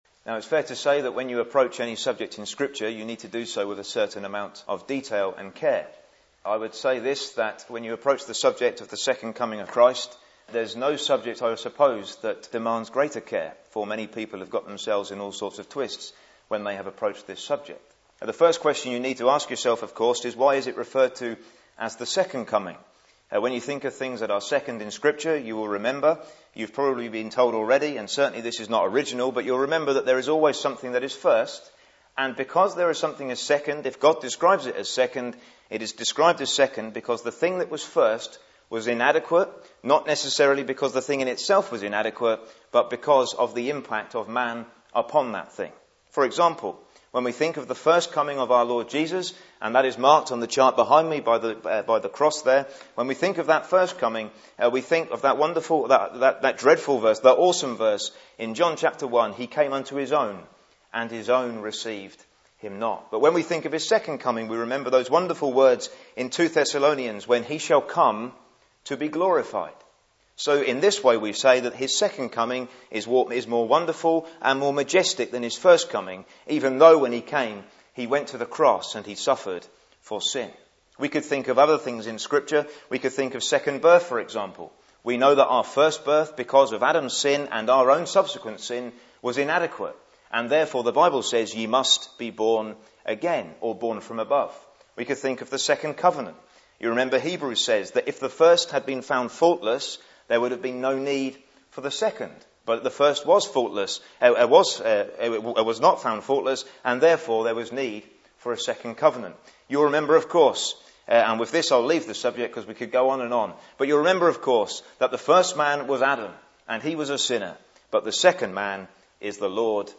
drawing particular attention to the pictures provided by the morning star and the sun of righteousness (Message preached 2nd April 2009)